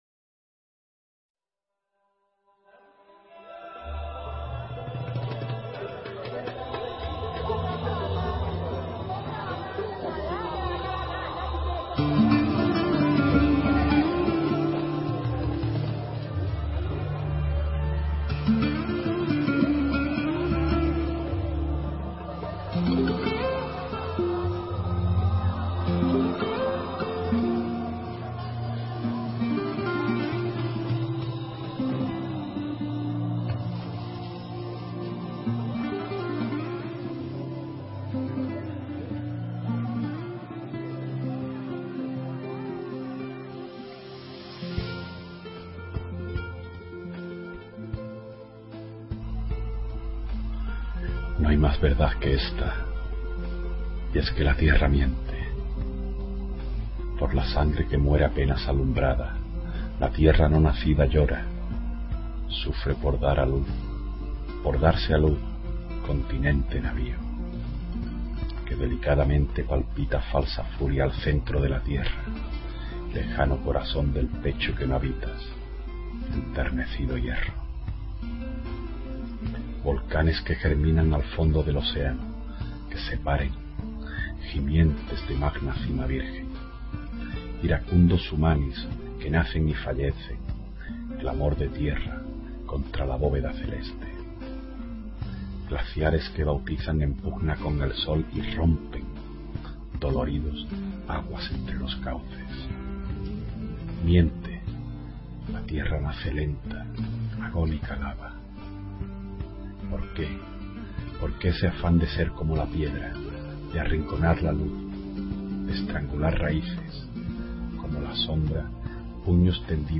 Inicio Multimedia Audiopoemas Alféizar de la tierra.